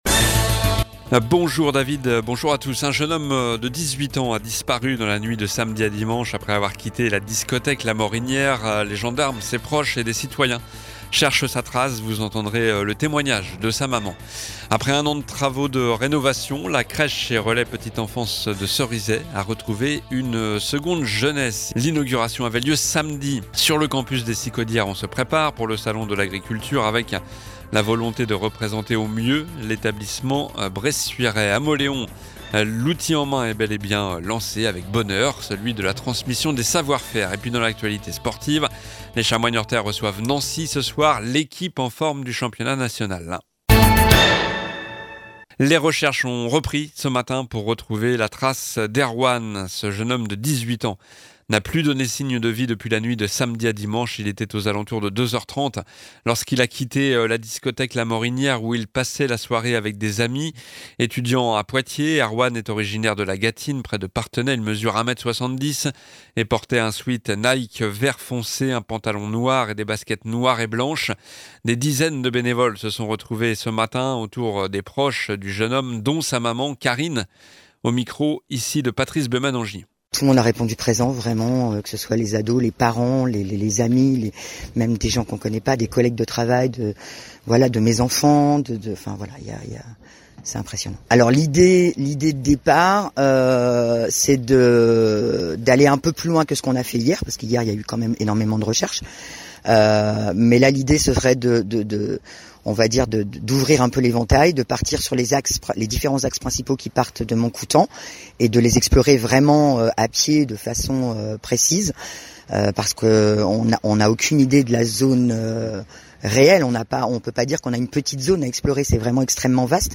Journal du lundi 12 février (midi)